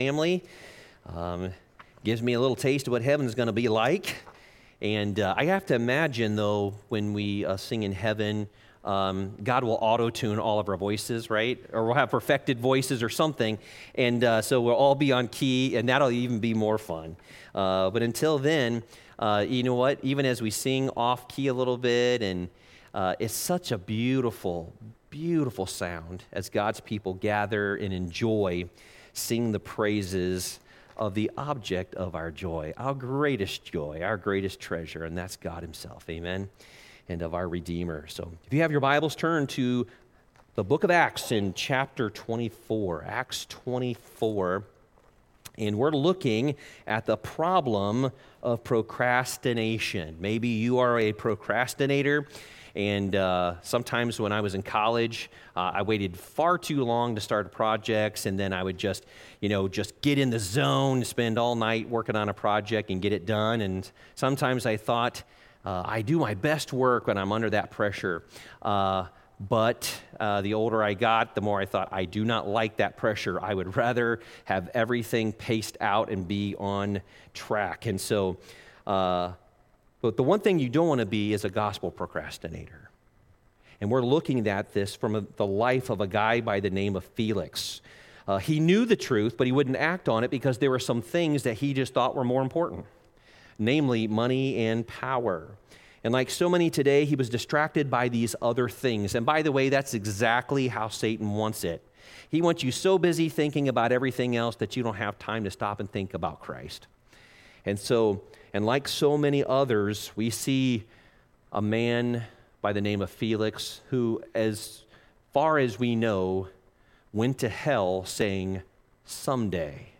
Worship Service 01/21/2024